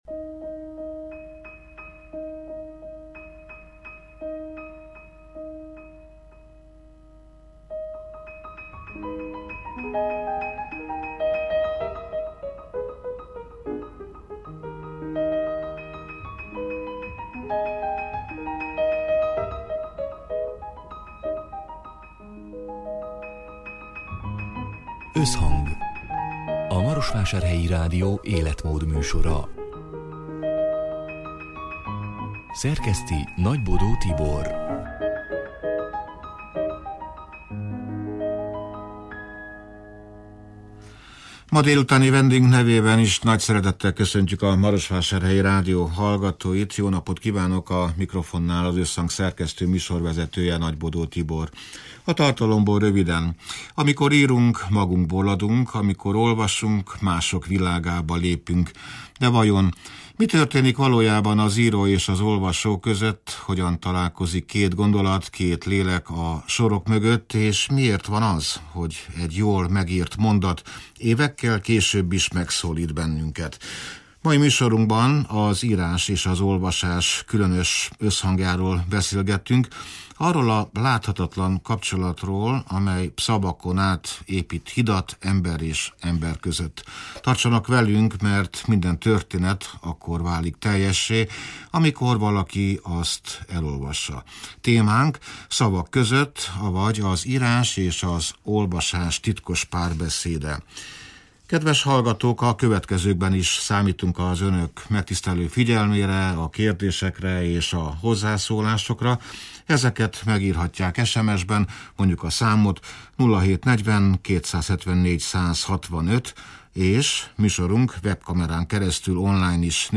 (elhangzott: 2026. március 4-én, szerdán délután hat órától élőben)